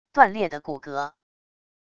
断裂的骨骼wav音频